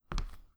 hard-footstep3.wav